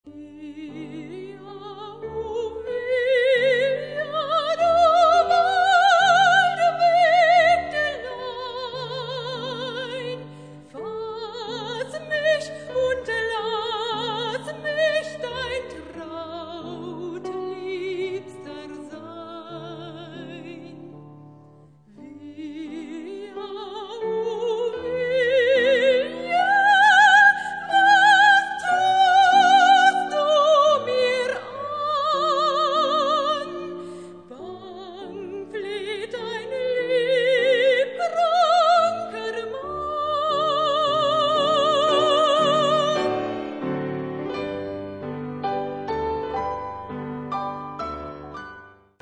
Sopran
Flügel